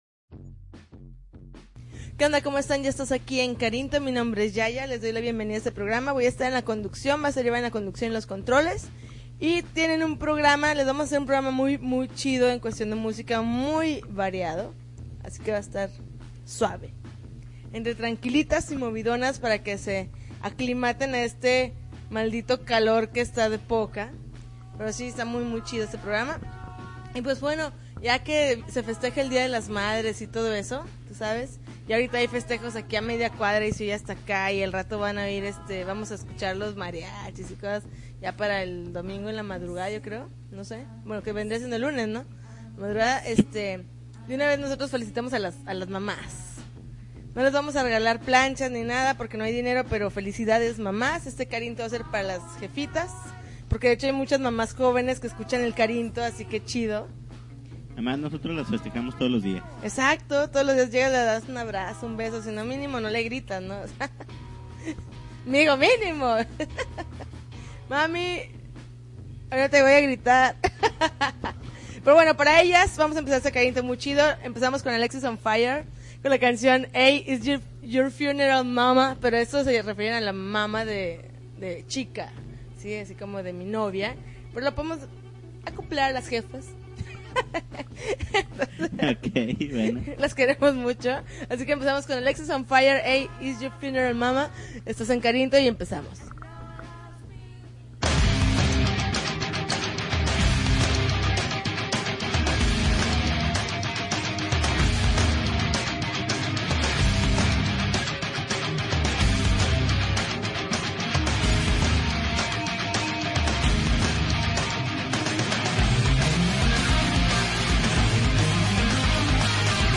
May 9, 2010Podcast, Punk Rock Alternativo